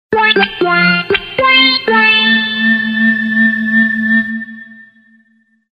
Dat synthesizer-muziekje met 6 tonen.